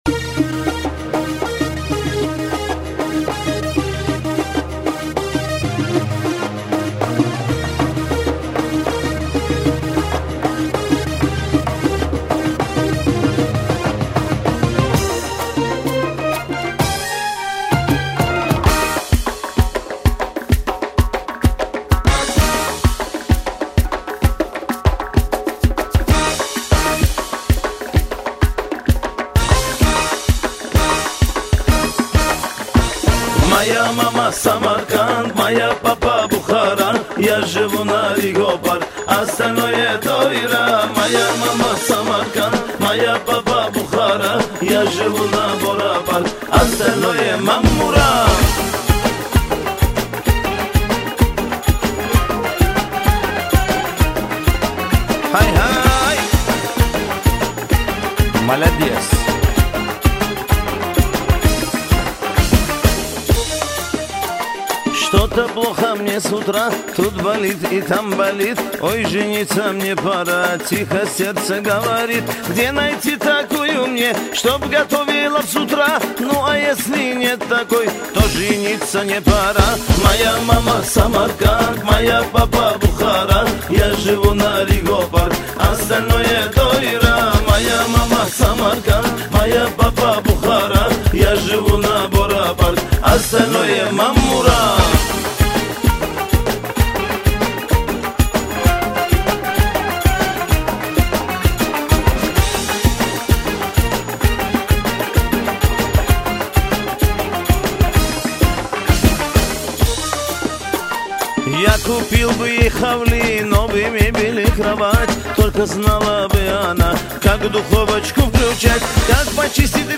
Категория: Эстрада | Добавил: Admin | Теги: бухарские евреи